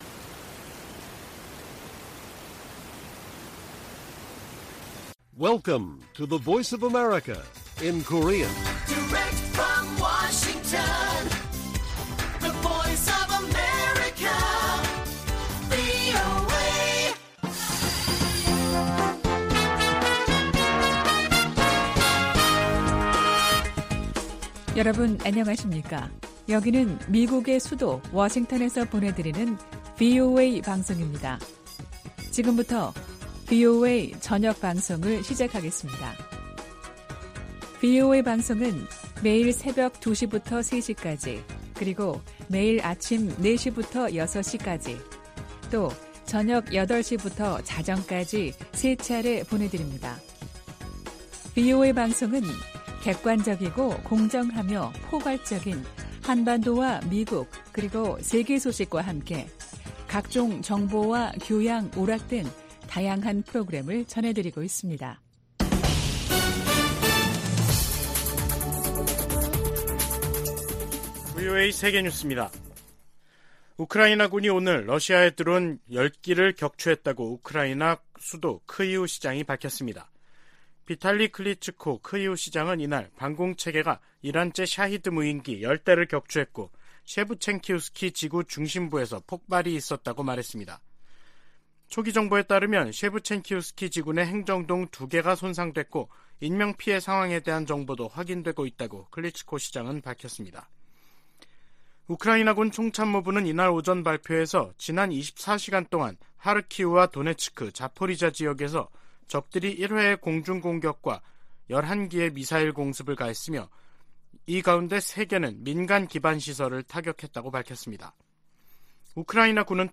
VOA 한국어 간판 뉴스 프로그램 '뉴스 투데이', 2022년 12월 14일 1부 방송입니다. 미국 국무부가 유럽연합(EU)의 대북 추가 독자제재 조치를 높이 평가하면서 북한 정권에 책임을 물리기 위해 동맹, 파트너와 협력하고 있다고 밝혔습니다. 북한의 인도주의 위기는 국제사회의 제재 때문이 아니라 김정은 정권의 잘못된 정책에서 비롯됐다고 유엔 안보리 대북제재위원장이 지적했습니다.